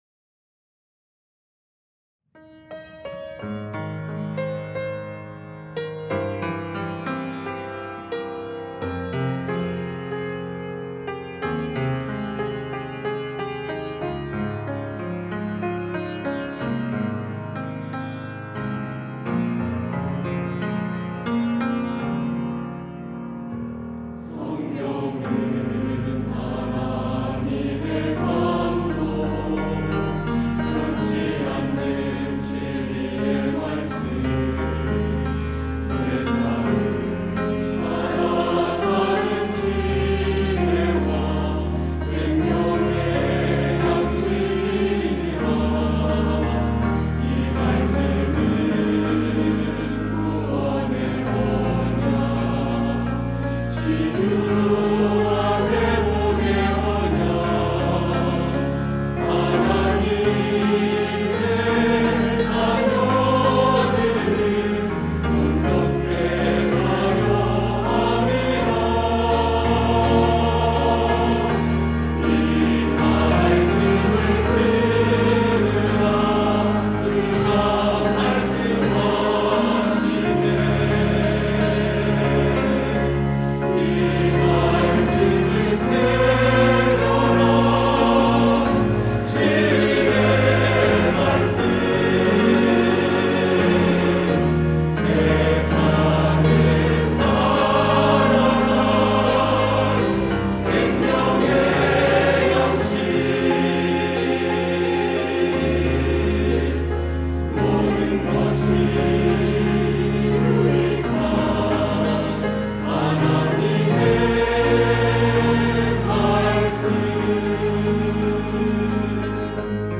할렐루야(주일2부) - 하나님의 말씀
찬양대